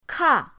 ka4.mp3